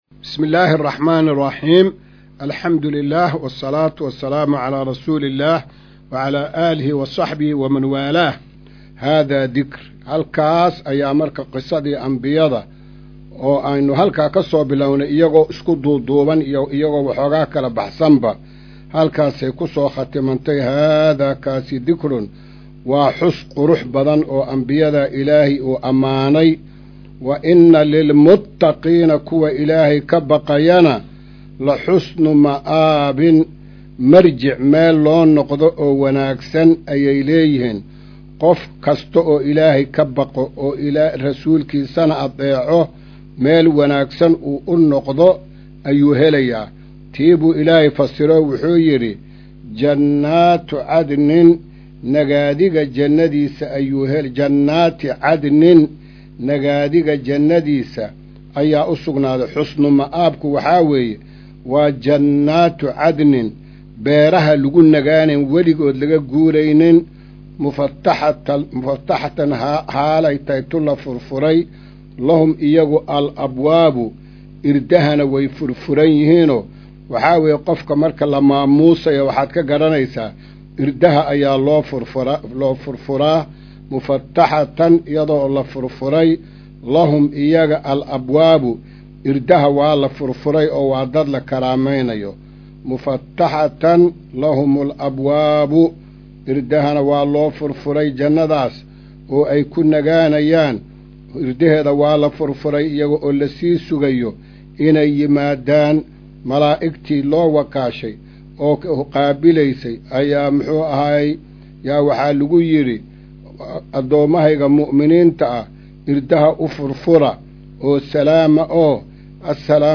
Maqal:- Casharka Tafsiirka Qur’aanka Idaacadda Himilo “Darsiga 217aad”